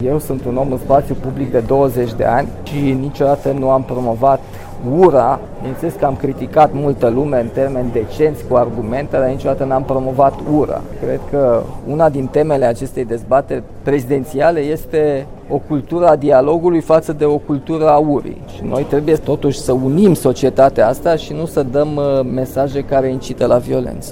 Prezent la acelaşi eveniment, primarul Capitalei, Nicuşor Dan, candidat independent la preşedinţie, s-a declarat pentru o cultură a dialogului în societatea românească şi a subliniat că politicienii trebuie să transmită mesaje de unitate: